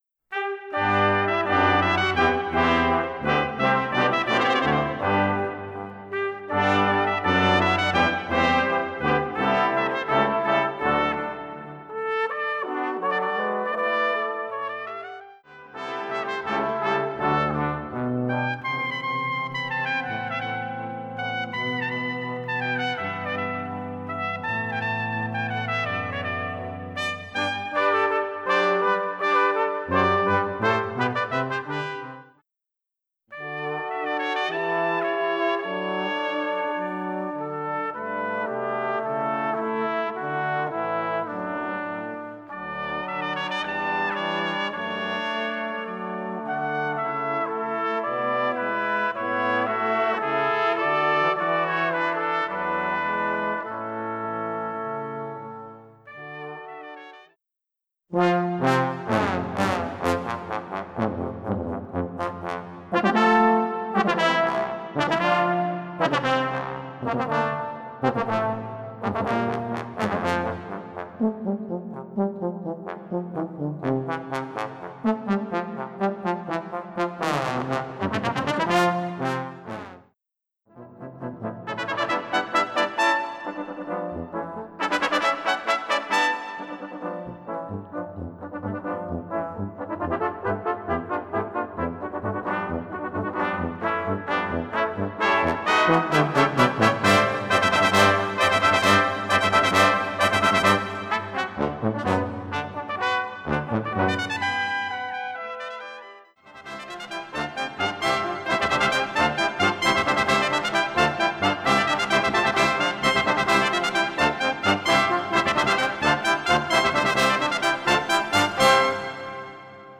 Bearbeitung im Stil Strawinskis Komponist
4 Trompeten in B Horn in F 4 Posaunen Tuba